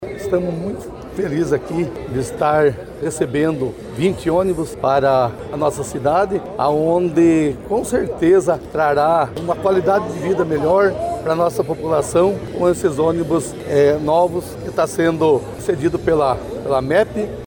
O prefeito de Fazenda Rio Grande, Luiz Sérgio Claudino, mais conhecido como Serjão (PP), comemorou os 20 veículos que o município vai receber.